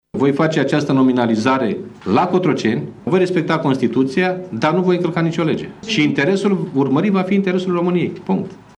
Președintele PSD, Liviu Dragnea, a declarat, joi seară, într-o conferință de presă, că nu ar fi indicat ca cineva să ignore faptul că PSD a câștigat fără echivoc aceste alegeri parlamentare.